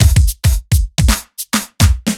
OTG_Kit 3_HeavySwing_110-C.wav